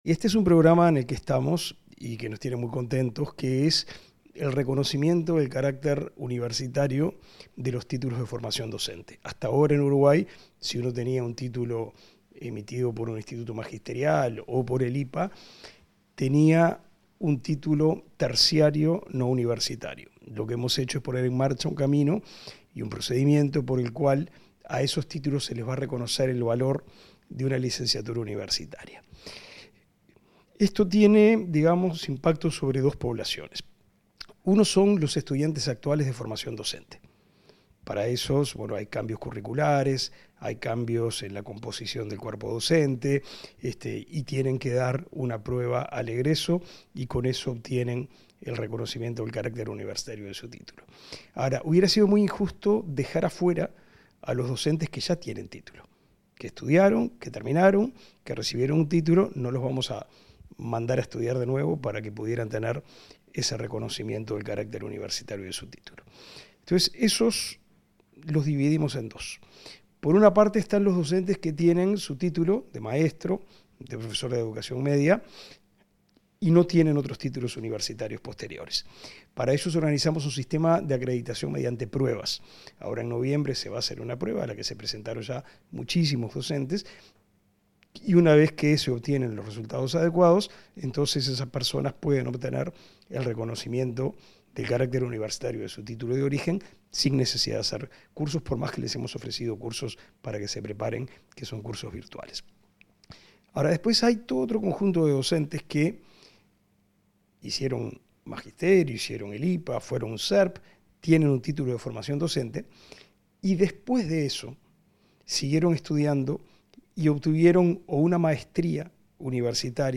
Entrevista al ministro de Educación y Cultura, Pablo da Silveira